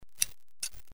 Warcorrespondents / Assets / 音效 / 接电报线.mp3
接电报线.mp3